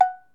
Cowbell_OS_3
Bell Cartoon Cow Cowbell Ding Dong H4n Ring sound effect free sound royalty free Movies & TV